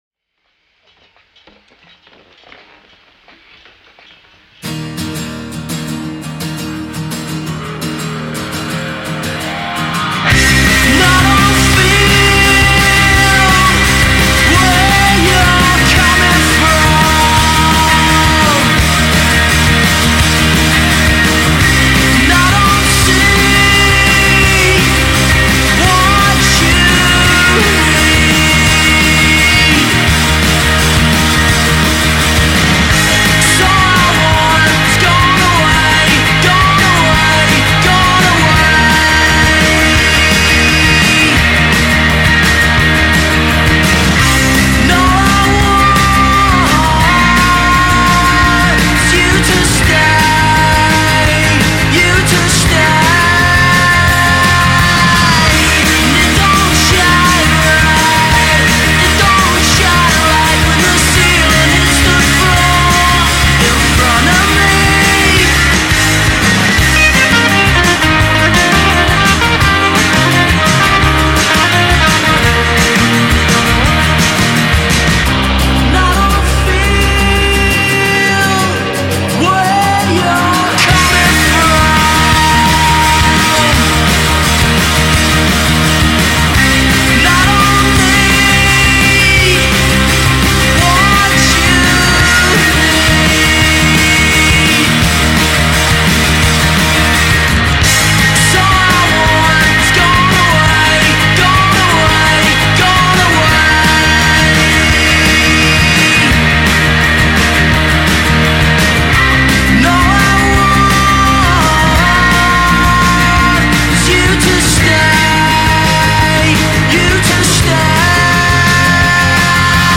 energiegeladenen song